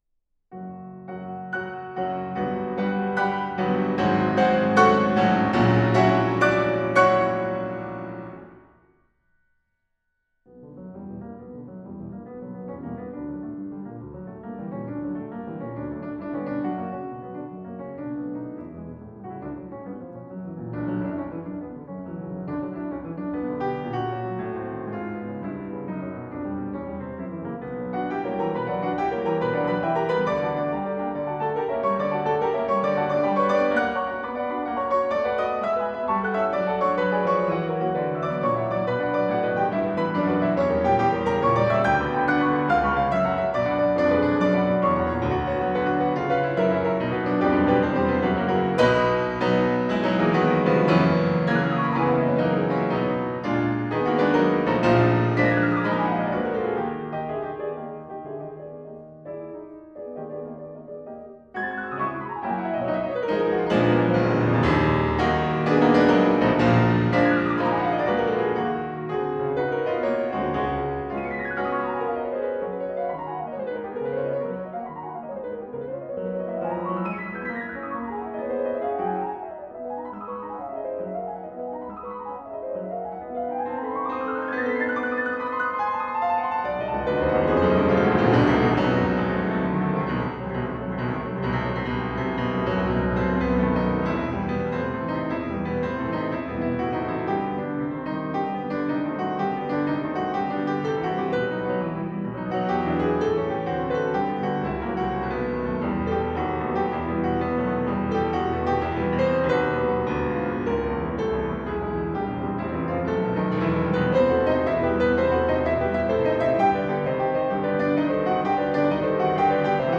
Index of /storage/ROSEDISK/Chopin Piano Sonatas